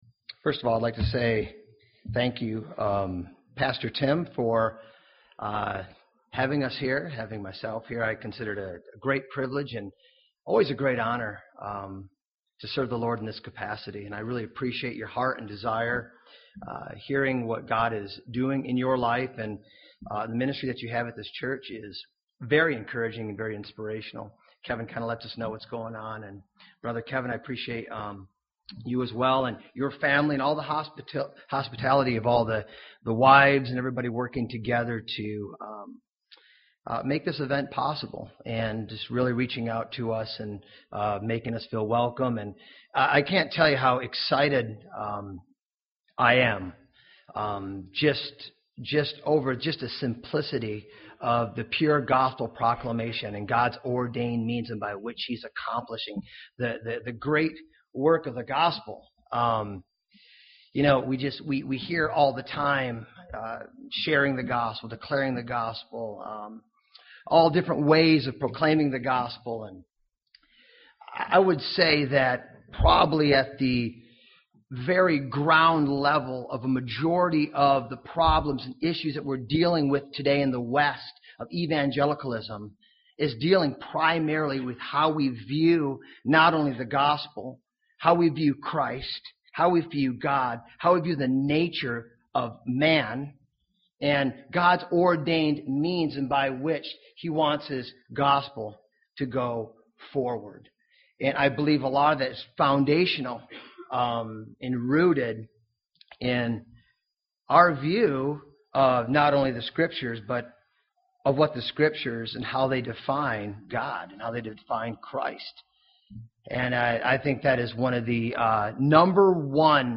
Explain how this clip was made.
Conference